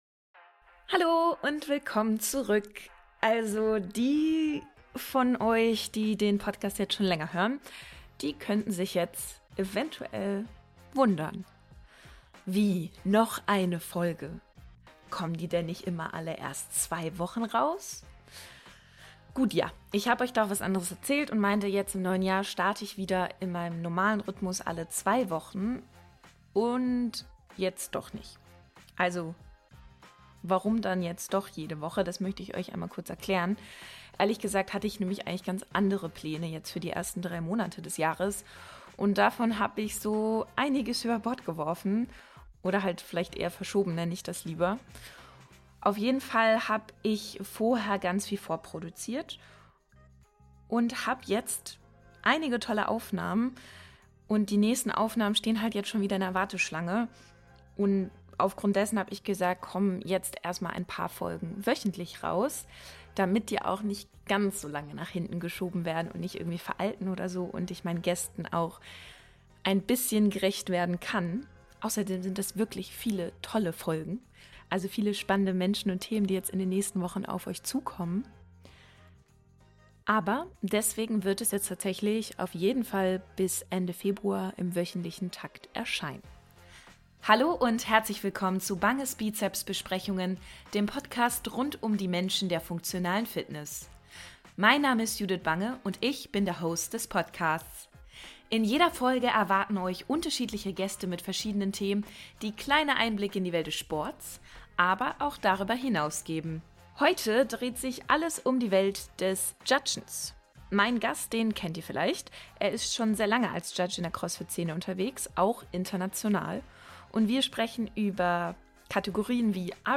im Gespräch ~ Banges Bizeps Besprechungen Podcast